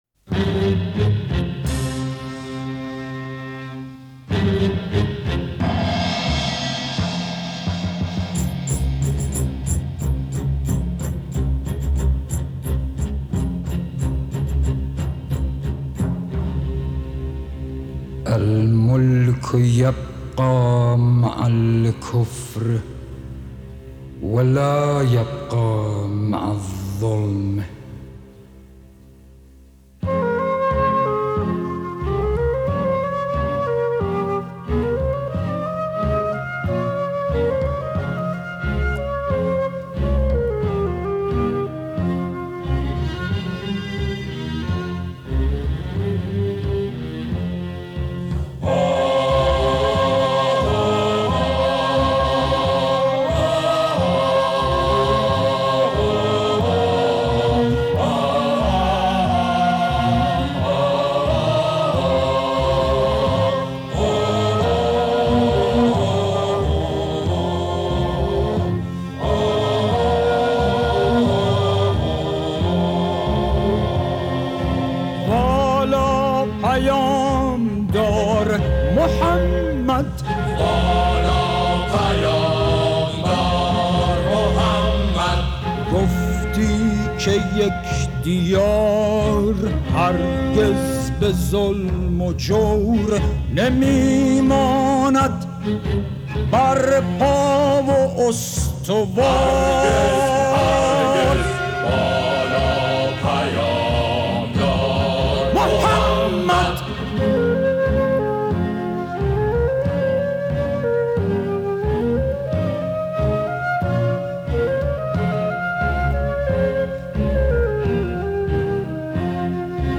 خش دار بود و گرفته؛ و با آنچه می‌خواند هم ساز بود.